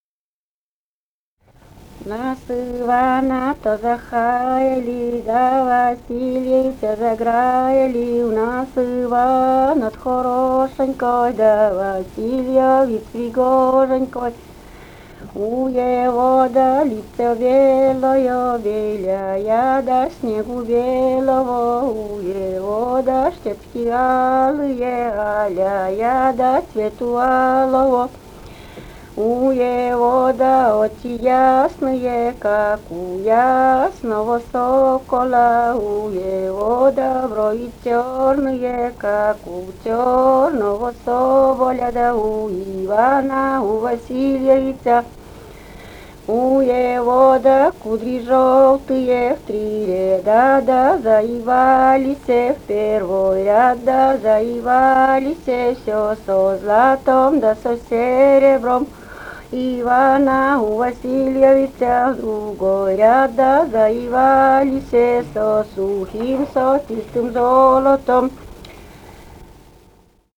«В нас Ивана-то захаяли» (свадебная).
Вологодская область, д. Малая Тигинского с/с Вожегодского района, 1969 г. И1130-13